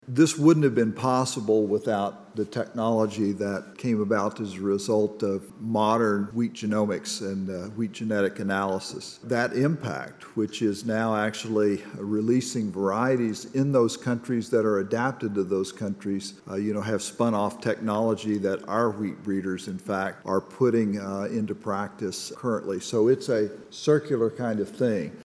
A panel discussion, hosted by Farm Journal Foundation and Kansas State University Tuesday at the Stanley Stout Center, focused on how agricultural innovations can mitigate global hunger and malnutrition.